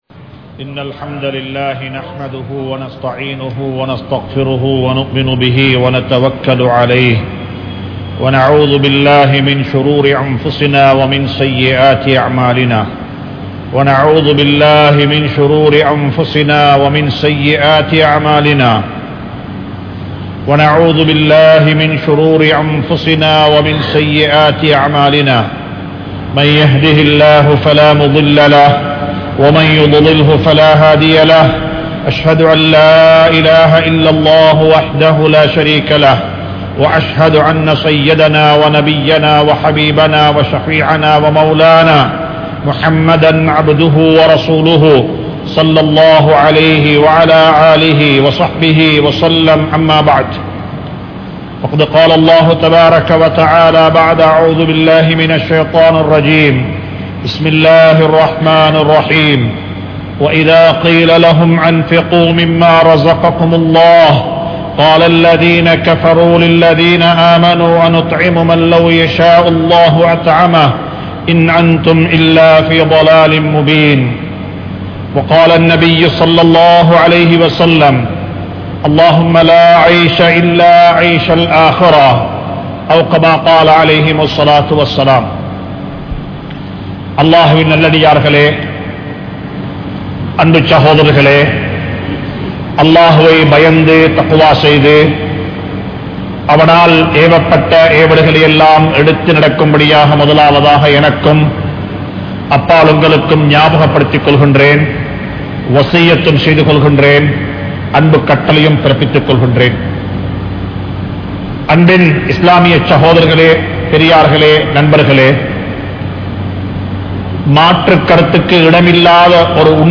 Uir Pirium Nimidam (உயிர் பிரியும் நிமிடம்) | Audio Bayans | All Ceylon Muslim Youth Community | Addalaichenai
Kollupitty Jumua Masjith